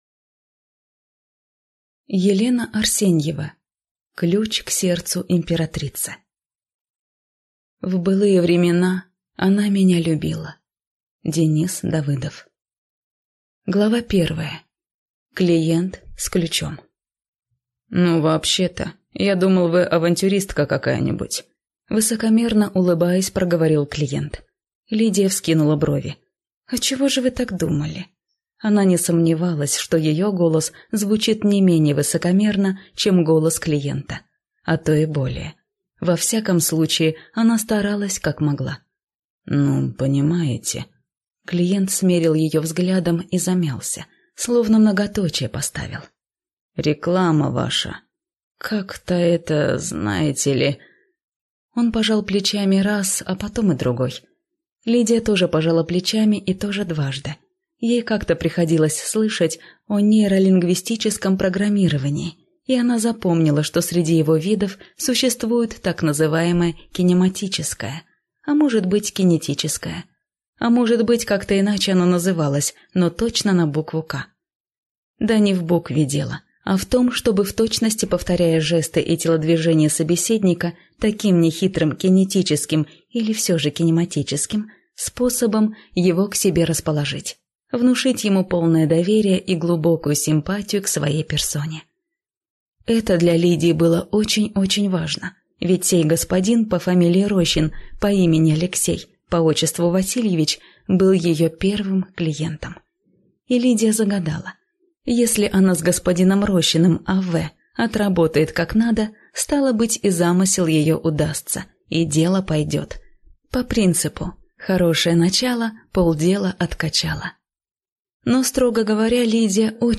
Аудиокнига Ключ к сердцу императрицы | Библиотека аудиокниг